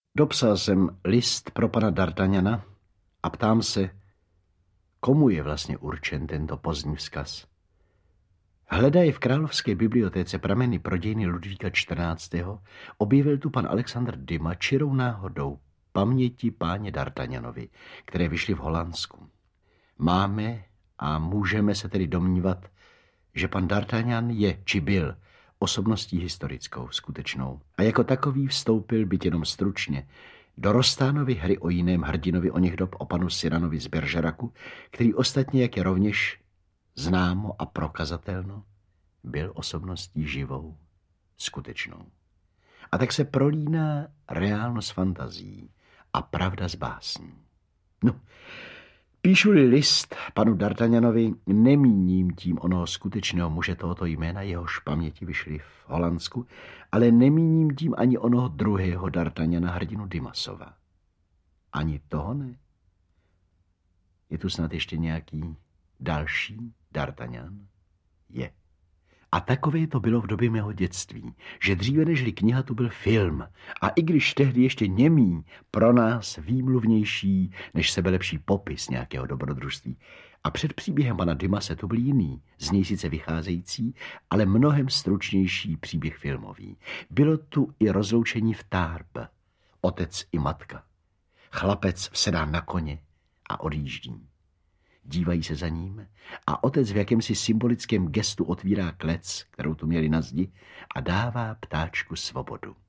Listy z Provence audiokniha
Ukázka z knihy